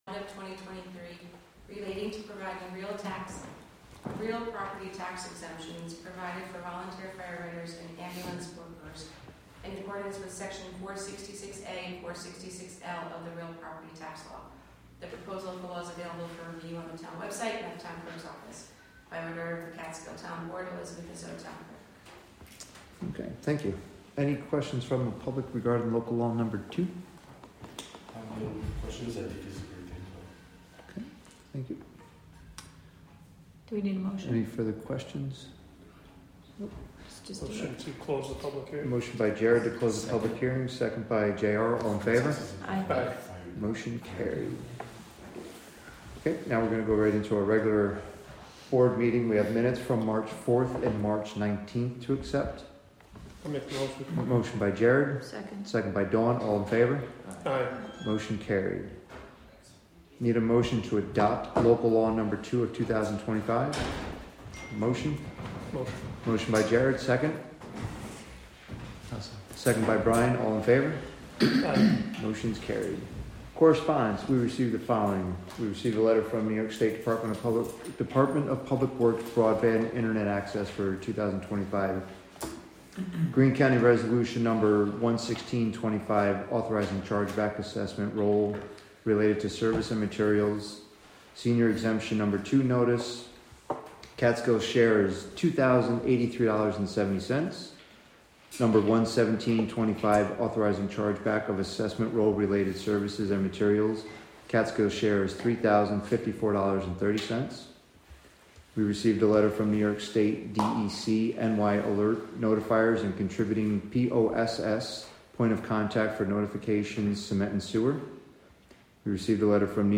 Live from the Town of Catskill: April 1, 2025 Catskill Town Board Meeting (Audio)